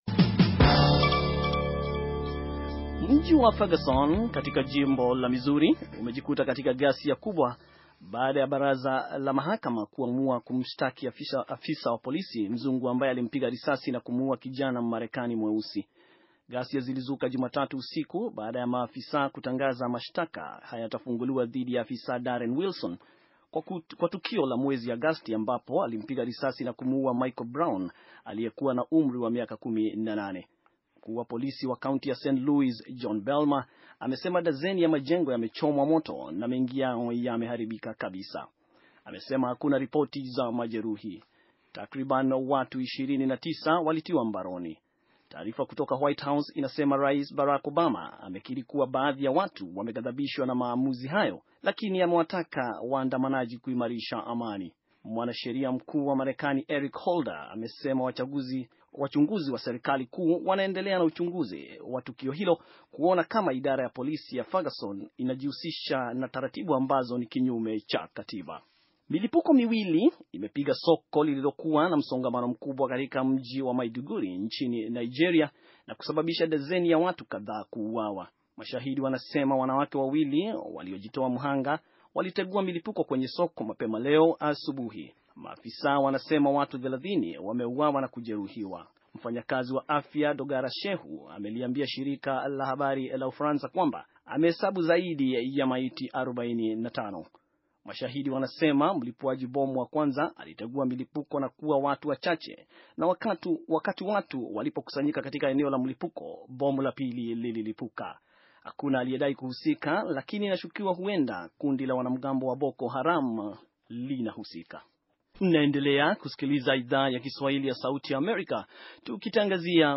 Taarifa ya habari - 6:42